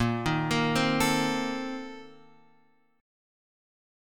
A#M7sus2sus4 Chord